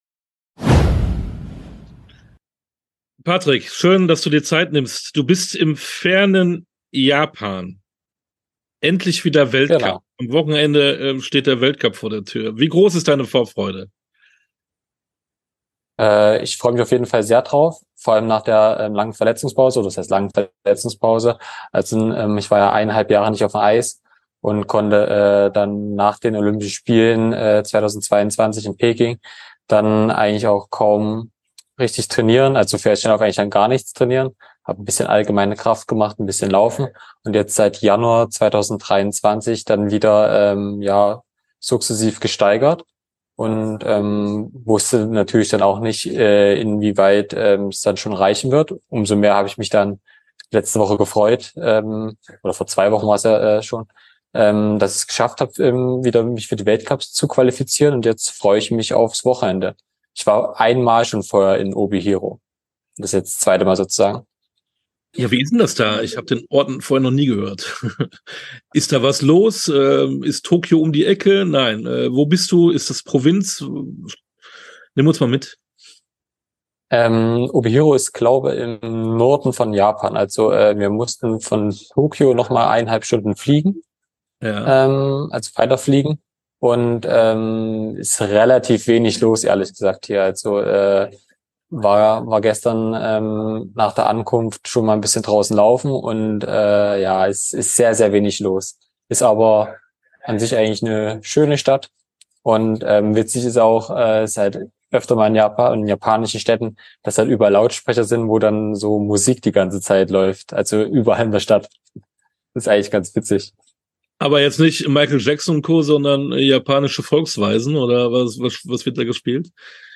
Sportstunde - Patrick Beckert-Eisschnelllaufer ~ Sportstunde - Interviews in voller Länge Podcast